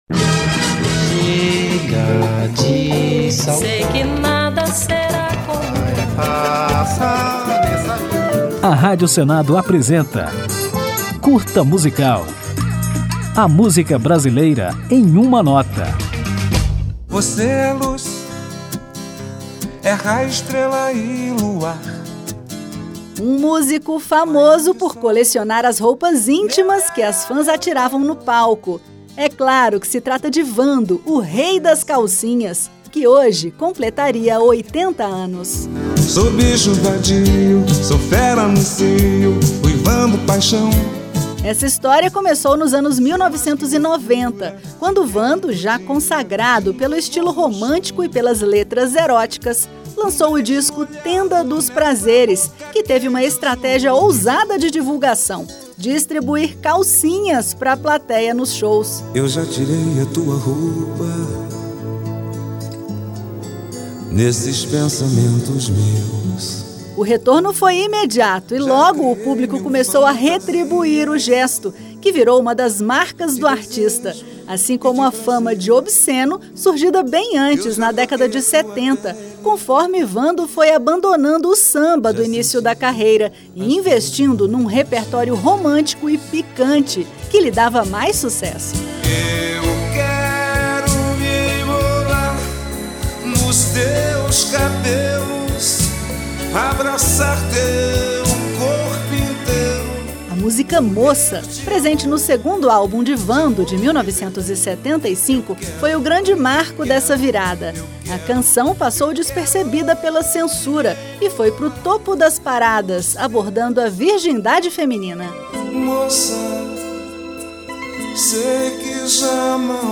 Para marcar a data, o Curta Musical conta a história deste ícone da música brasileira, conhecido como o Rei das Calcinhas, que ficou famoso pelo romantismo obsceno de suas músicas. Ao final desta homenagem, ouviremos Fogo & Paixão, o maior sucesso de Wando.